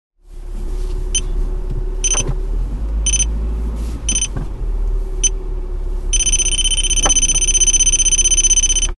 На этой странице собраны звуки парктроника — сигналы, которые издает автомобиль при парковке или обнаружении препятствий.
звук парковочного датчика